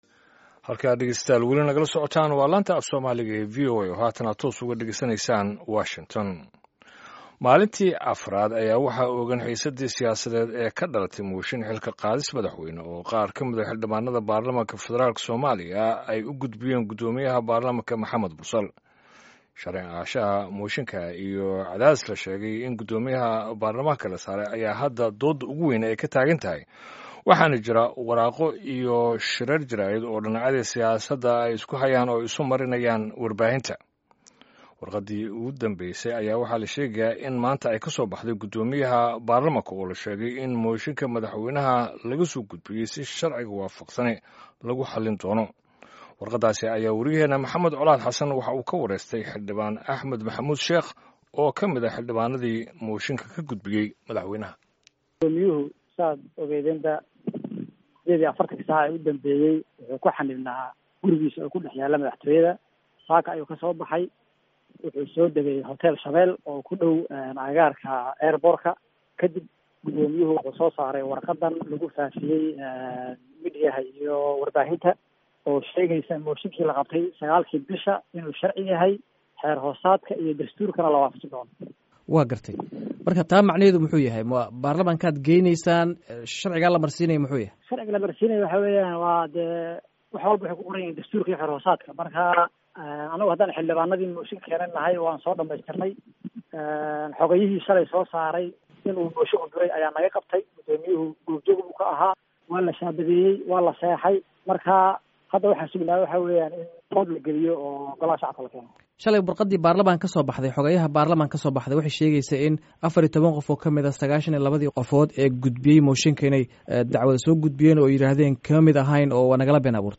Guddoomiyaha baarlamanka Soomaaliya Maxamed Mursal Cabdiraxmaan ayaa sheegay in mooshinka laga gudbiyey madaxweyne Maxamed Cabdullaahi Farmaajo uu weli taagan yahay. (Dhageyso wareysi ku saabsan arrintan)